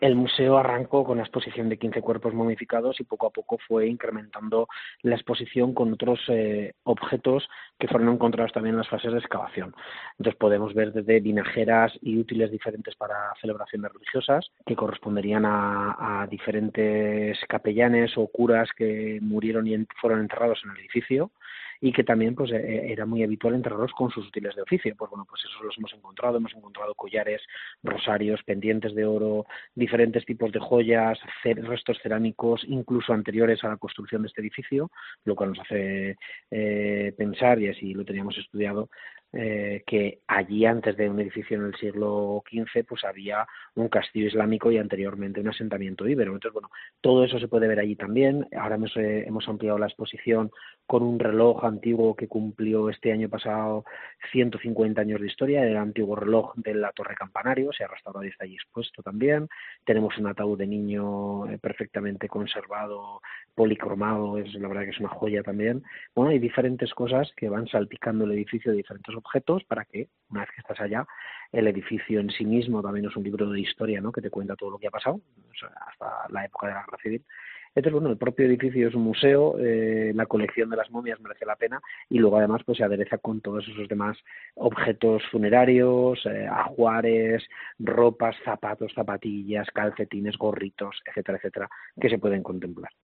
Entrevista a Jesús Morales, alcalde de Quinto, que habla del único museo de momias que hay en España